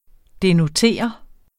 Udtale [ denoˈteˀʌ ]